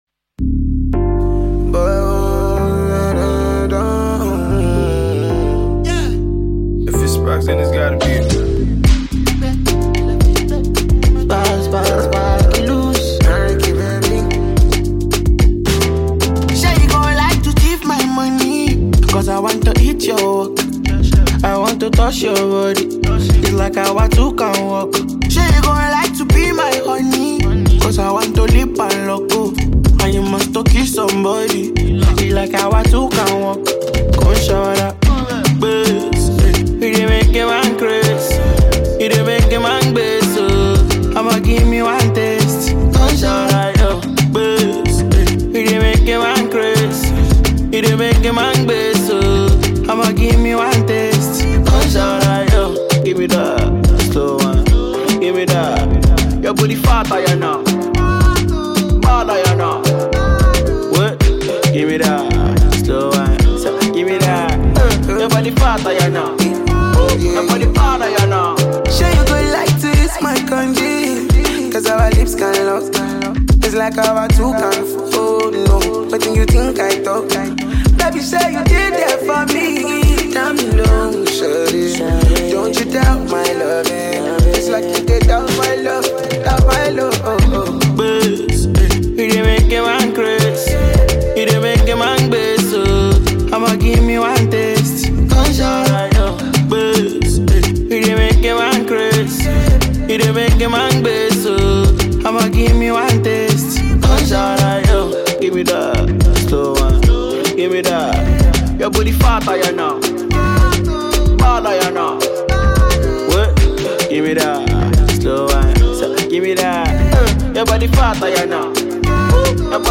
street banger